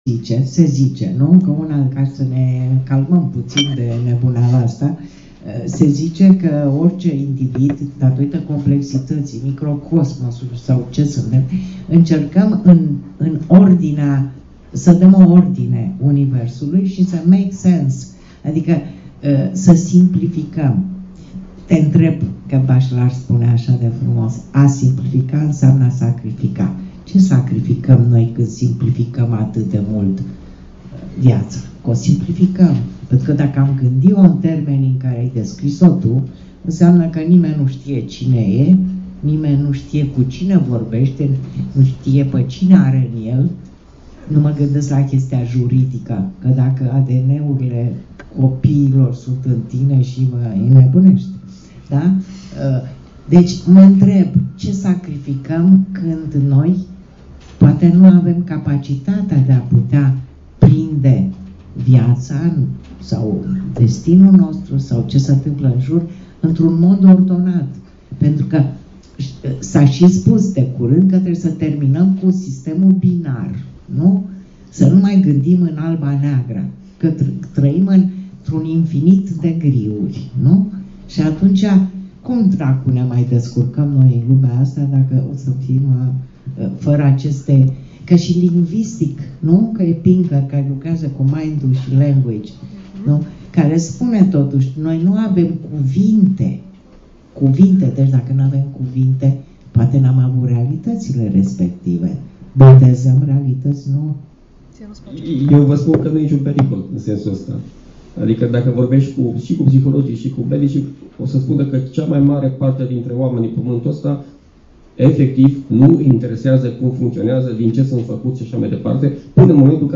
Dezbatere "Romanii la psiholog"
Pe 6 noiembrie, la libraria Carturesti Verona, a avut loc dezbaterea Psychologies "Romanii la psiholog": o discutie lejera, cu umor, despre romani si dilemele lor, stres, pesimism si optimism.